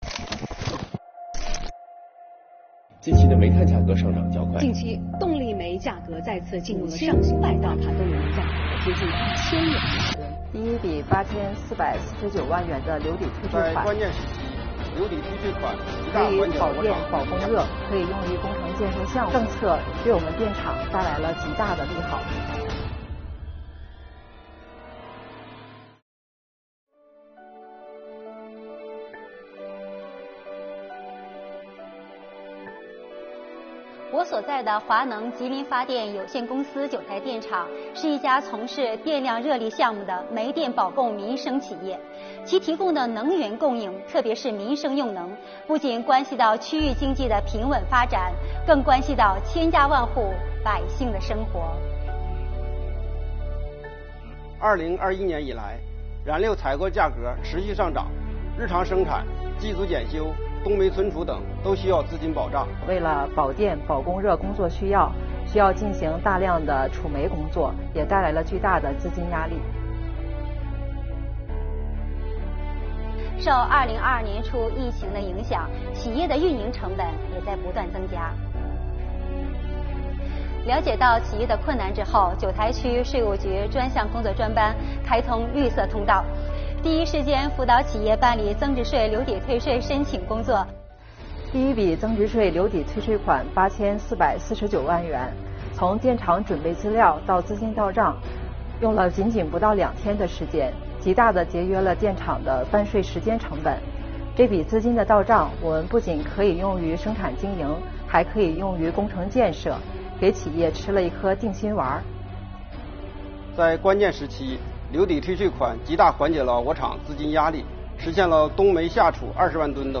作品以人物采访的方式展开叙事，大胆加入运动镜头，具有很强的代入感。